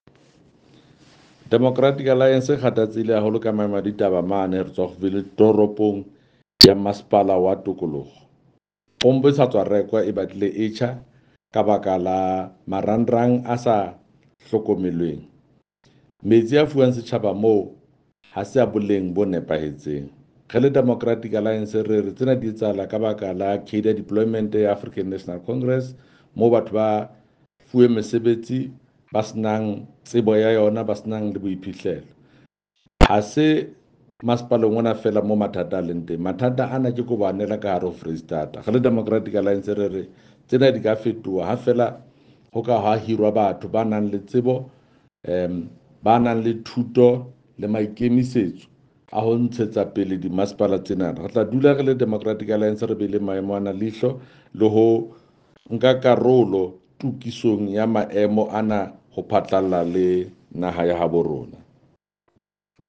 Sesotho soundbite by Jafta Mokoena MPL with images here and here